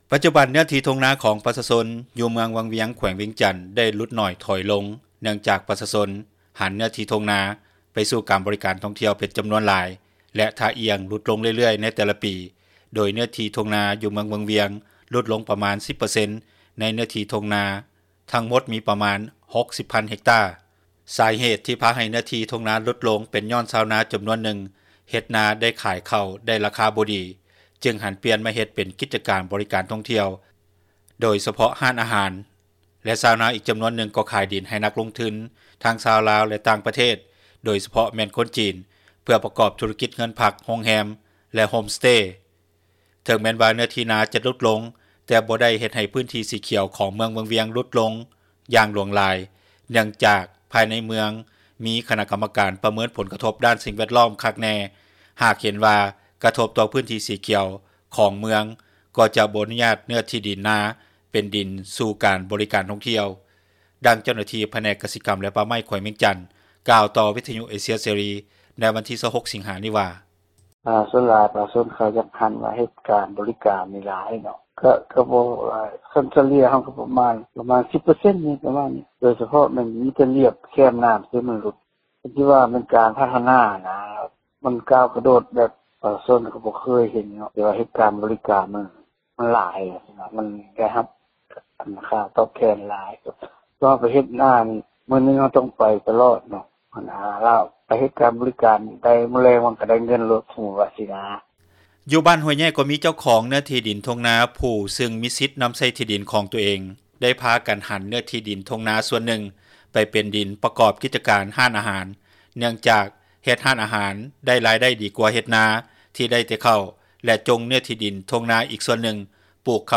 ດັ່ງນັກທ່ອງທ່ຽວ ຈາກເມືອງແປກ ແຂວງຊຽງຂວາງ ມາທ່ຽວເມືອງວັງວຽງ ແຂວງວຽງຈັນກ່າວ ໃນມື້ດຽວກັນນີ້ວ່າ:
ດັ່ງທ່ານ ບຸນຈັນ ມະລະວົງ ເຈົ້າເມືອງວັງວຽງກ່າວວ່າ: